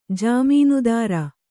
♪ jāmīnudāra